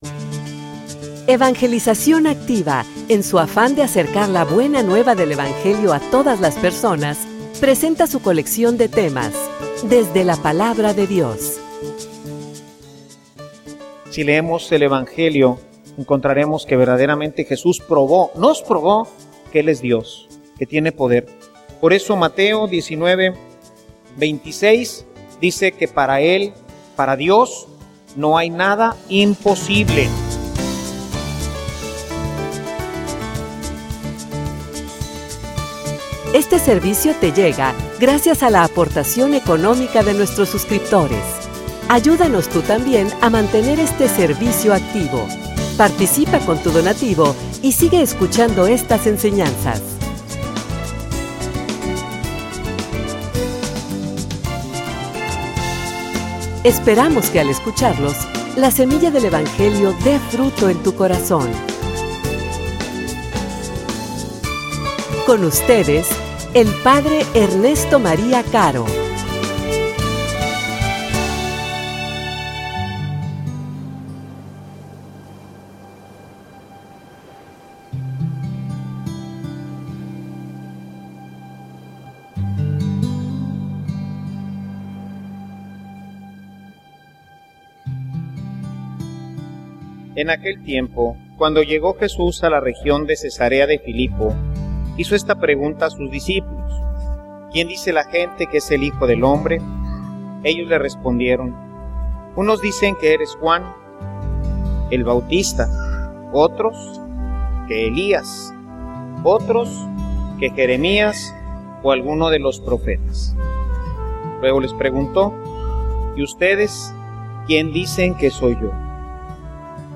homilia_Una_afirmacion_que_compromete.mp3